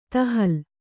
♪ tahal